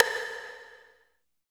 90 STICK  -L.wav